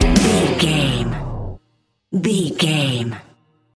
Aeolian/Minor
F#
drums
electric guitar
bass guitar
hard rock
aggressive
energetic
intense
nu metal
alternative metal